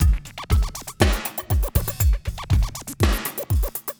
The stalker (Drums) 120BPM.wav